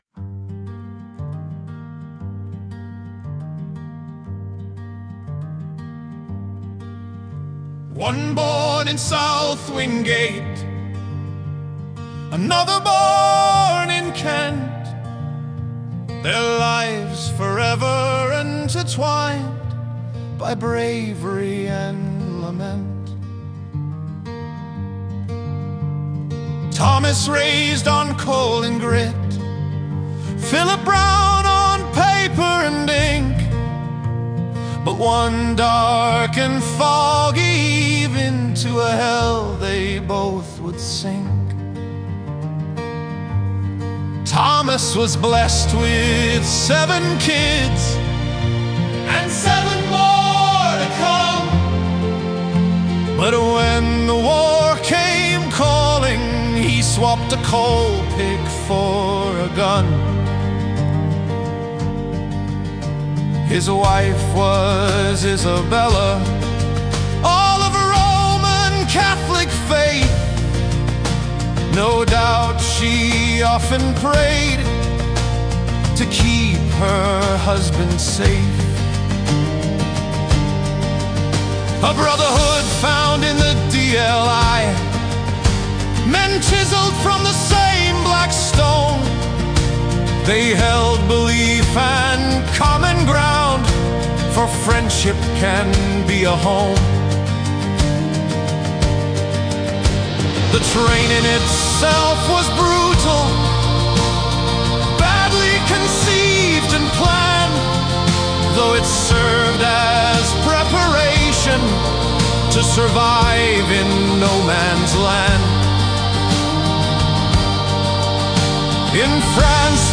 You can listen to this set to music by clicking here